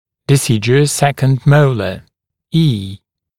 [dɪ’sɪdjuəs ‘sekənd ‘məulə], [iː] [ди’сидйуэс ‘сэкэнд ‘моулэ] молочный второй моляр (также E)